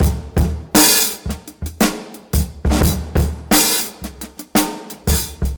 86 Bpm Drum Groove D Key.wav
Free breakbeat - kick tuned to the D note.
.WAV .MP3 .OGG 0:00 / 0:06 Type Wav Duration 0:06 Size 958 KB Samplerate 44100 Hz Bitdepth 16 Channels Stereo Free breakbeat - kick tuned to the D note.
86-bpm-drum-groove-d-key-rph.ogg